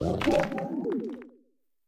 Cri de Tomberro dans Pokémon Écarlate et Violet.